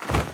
LootPop.wav